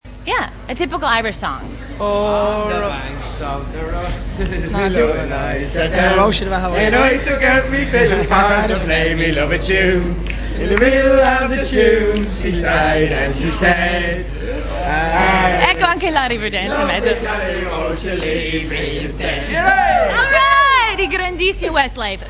An Irish Harmony from the EMAs
This little interview was made for MTV Select
Then she told that in Italy we've got lots of typical harmonies... and she asked them to sing a typical Irish harmony...
...Bry starts singing, but then you can here the manly Kian's voice!!!